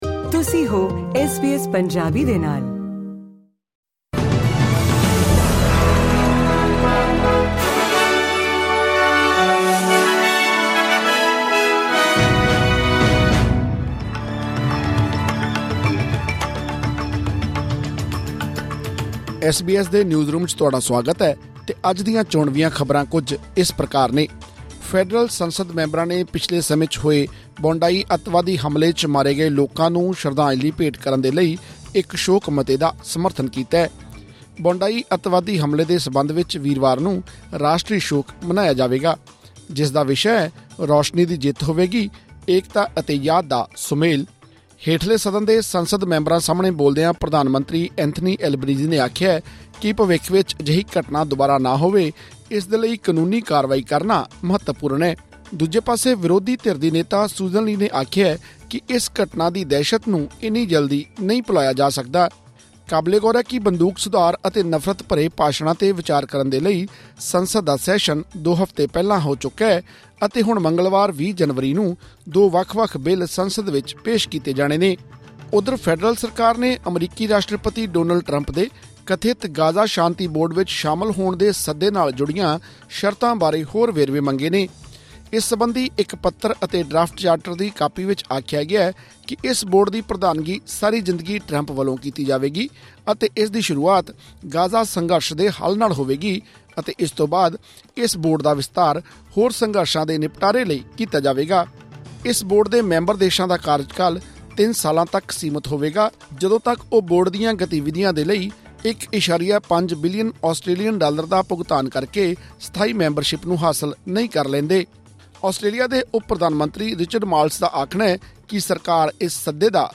ਖ਼ਬਰਨਾਮਾ: ਬੌਡਾਈ ਅੱਤਵਾਦੀ ਹਮਲੇ ਸਬੰਧੀ ਵੀਰਵਾਰ ਨੂੰ ਮਨਾਇਆ ਜਾਵੇਗਾ ‘ਰਾਸ਼ਟਰੀ ਸ਼ੋਕ’, ਸੰਸਦ ਵਿੱਚ ਮਤਾ ਪਾਸ